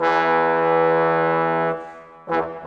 Rock-Pop 07 Trombones _ Tuba 04.wav